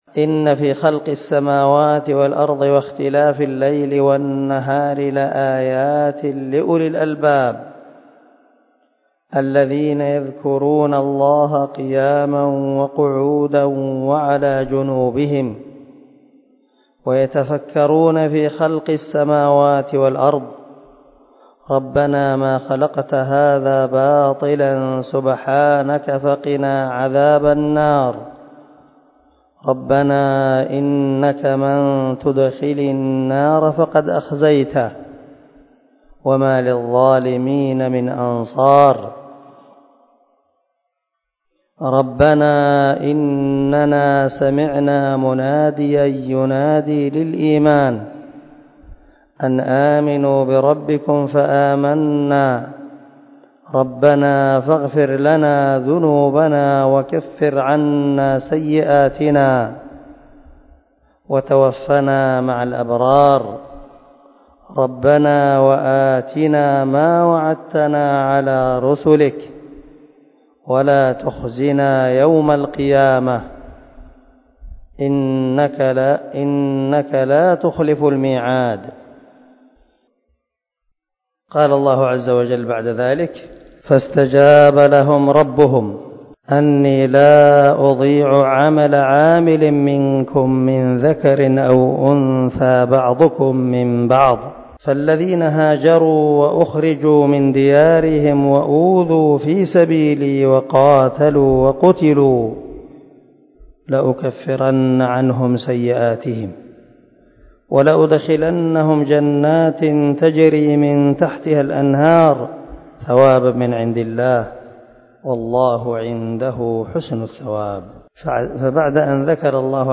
230الدرس 75 تفسير آية ( 195 ) من سورة آل عمران من تفسير القران الكريم مع قراءة لتفسير السعدي